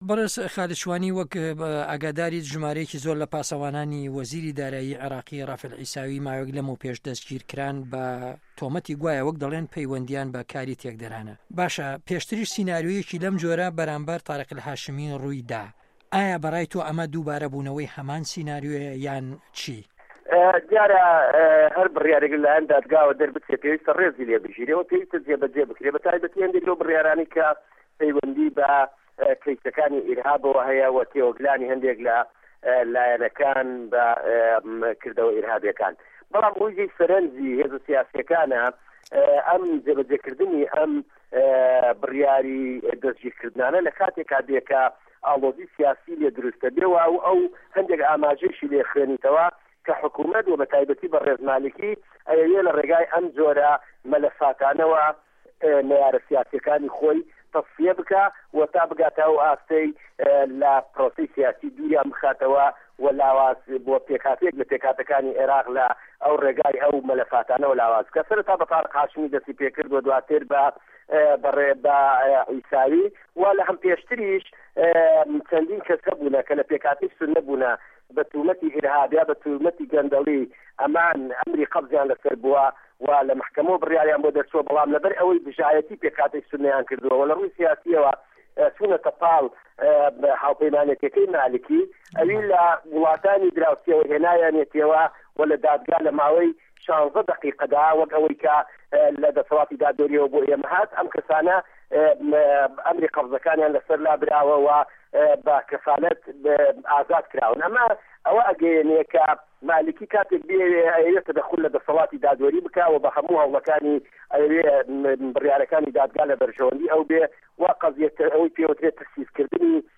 وتووێژی خالید شوانی